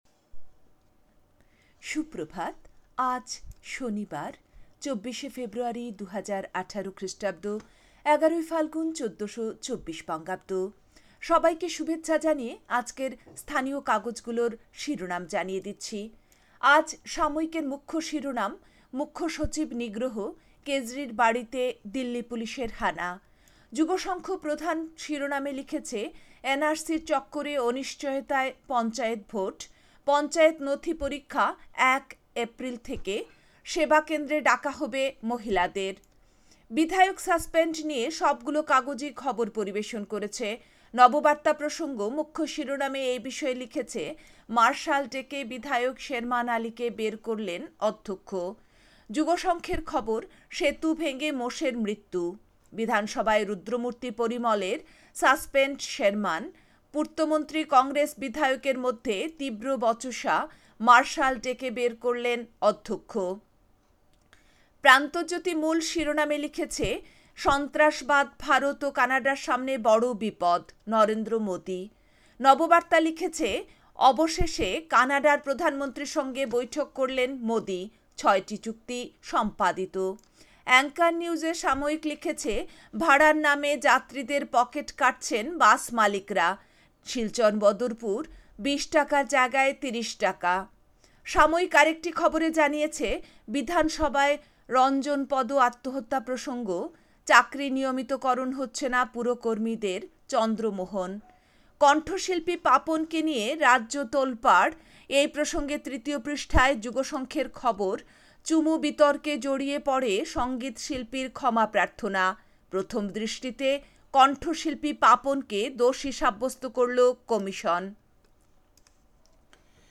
A quick bulletin with all top news